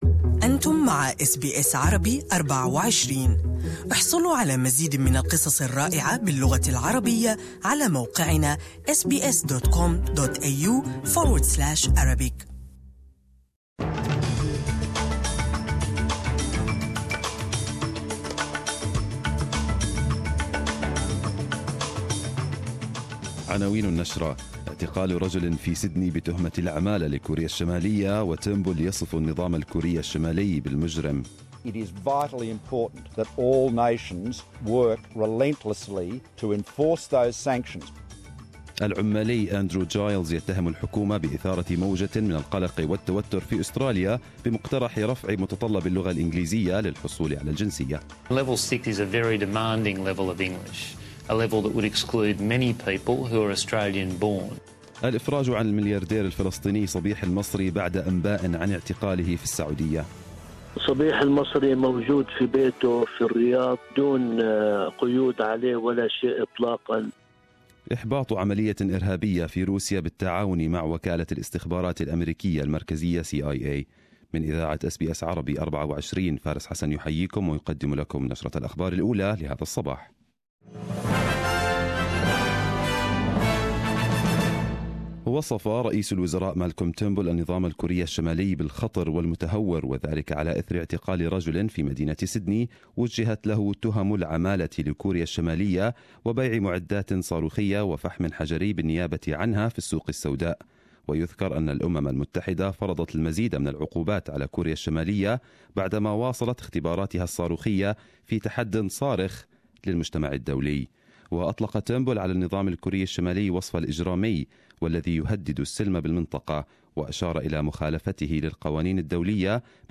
Arabic News Bulletin 18/12/2017